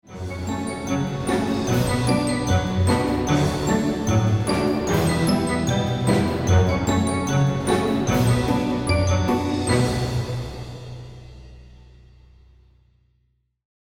countDown.mp3